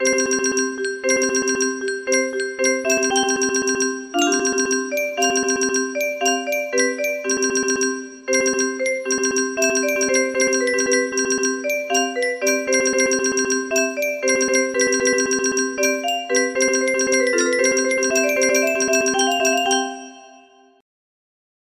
eine kleide music box melody